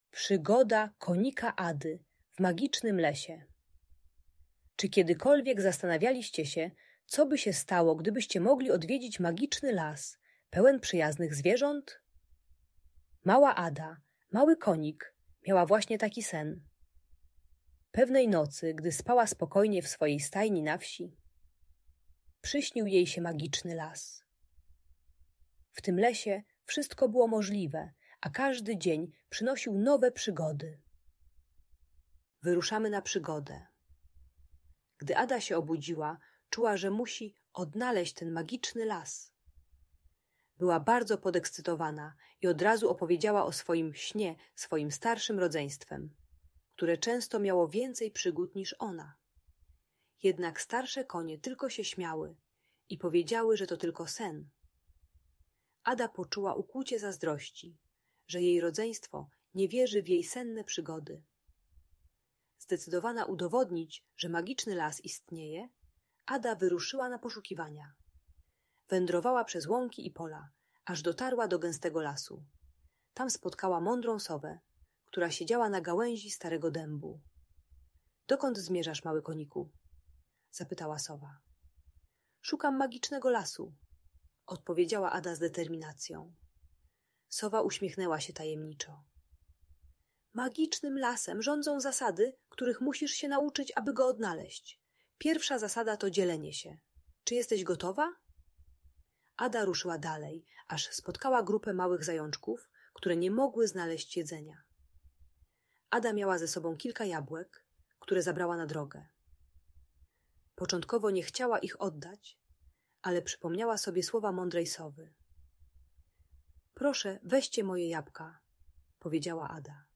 Przygoda Konika Ady w Magicznym Lesie - Rodzeństwo | Audiobajka